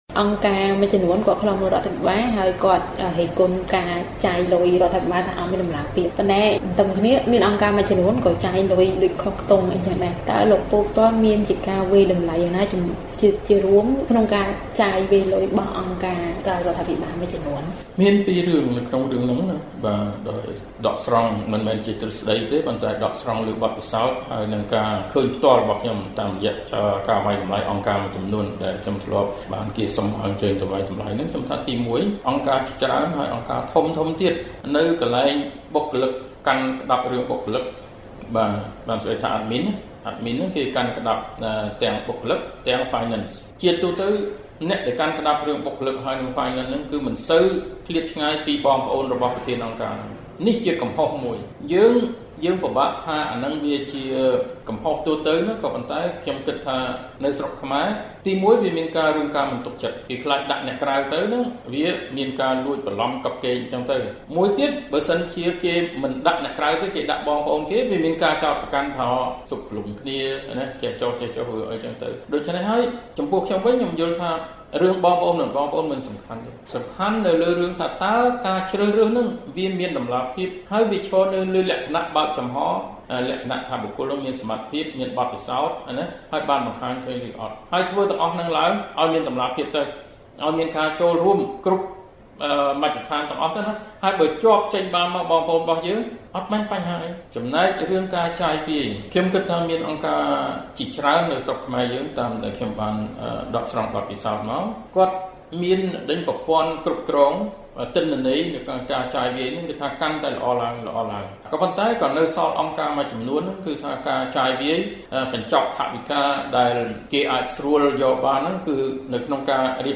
បទសម្ភាសន៍ VOA៖ តម្លាភាពហិរញ្ញវត្ថុត្រូវមានទាំងក្នុងរដ្ឋាភិបាល និងអង្គការក្រៅរដ្ឋាភិបាល